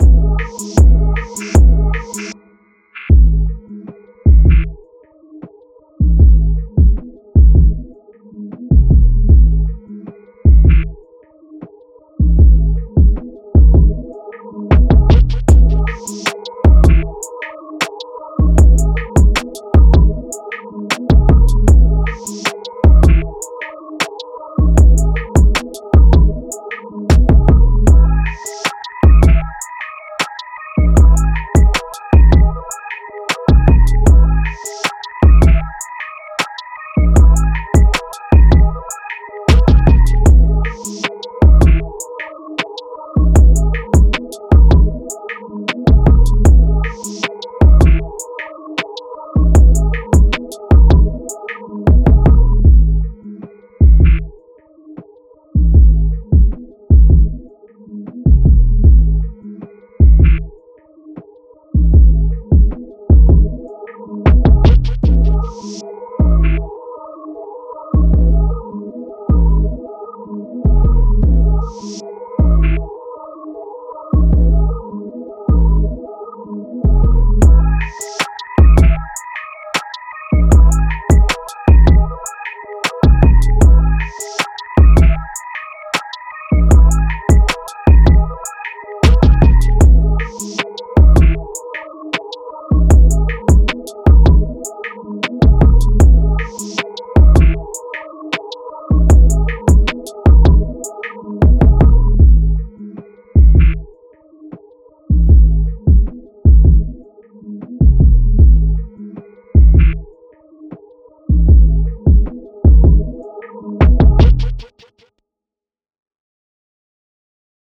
Rap
Ab Minor
Trap rap, getting money and leveling up